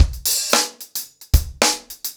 DaveAndMe-110BPM.37.wav